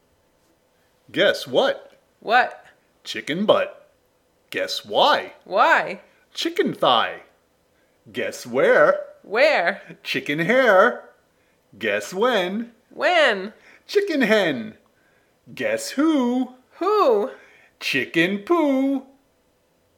Guess What? Chicken Butt! - Chansons enfantines américaines